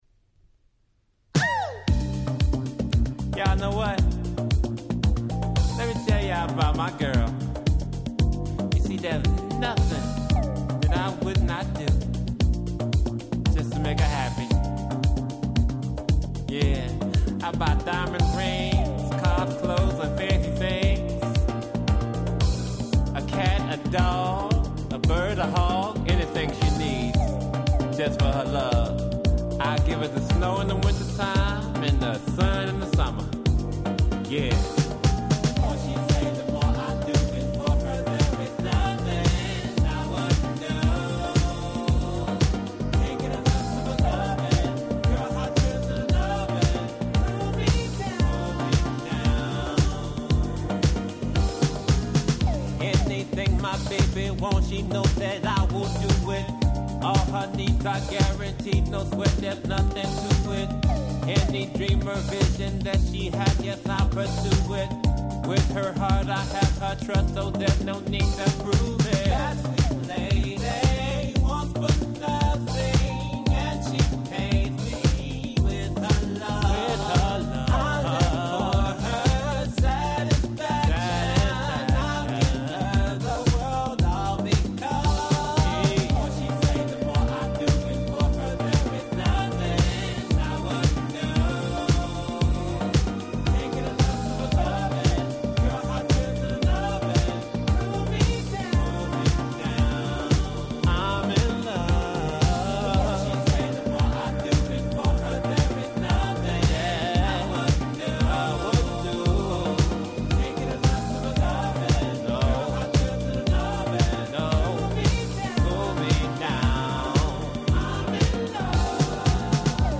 聴いていてワクワクしてしまう様な素敵なBoogieやModern Soulがてんこ盛りです！！